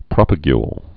(prŏpə-gyl)